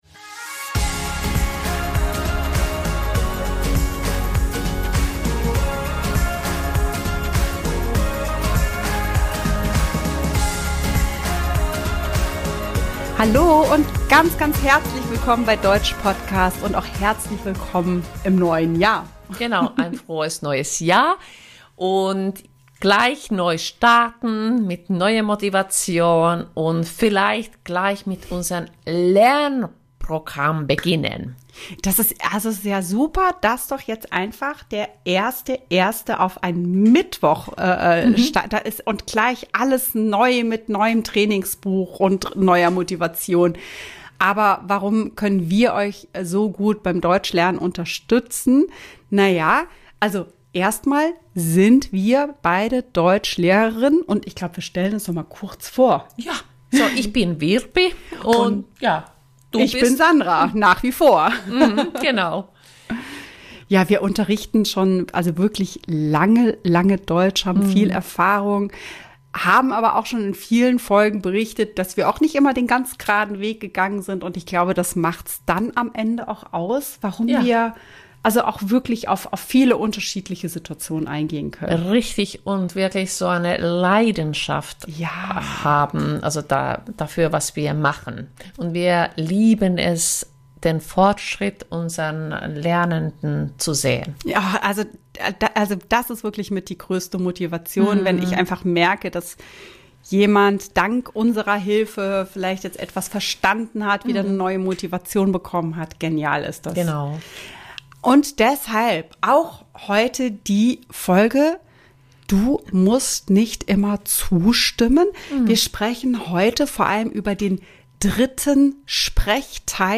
zwei zertifizierte Dozentinnen, Prüferinnen und unterrichten schon lange Deutsch als Fremd- und Zweitsprache. In jeder Woche präsentieren wir Euch eine neue Folge, in der wir über ein Thema sprechen und Euch an einigen Beispielen wichtige Inhalte der deutschen Grammatik vermitteln.